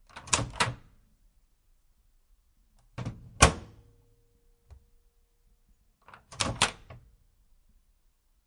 随机 " 微波炉打开关闭
Tag: 微波 打开 关闭